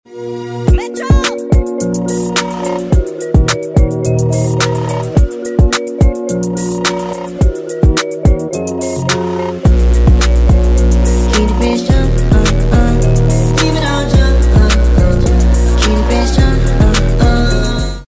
Nouveau diffuseur TCR+ catback ragazzon#golf7gti sound effects free download